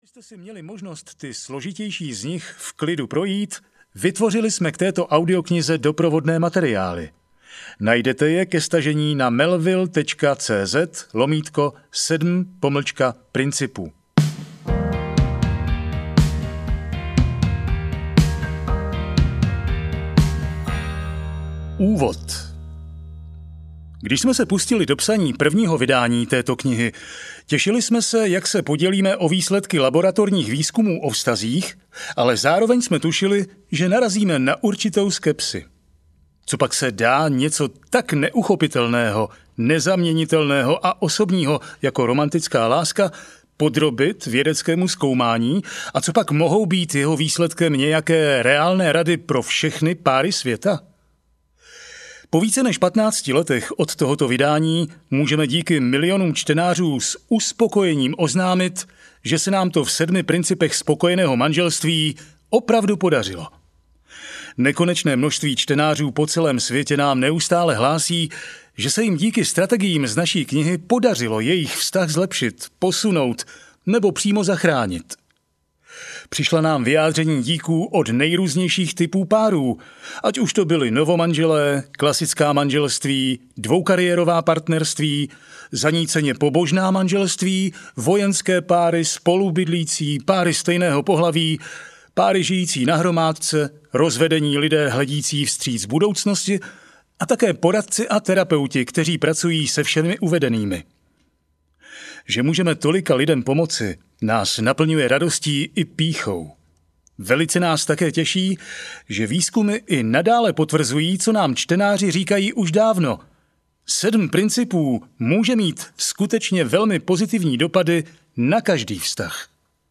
Sedm principů spokojeného manželství audiokniha
Ukázka z knihy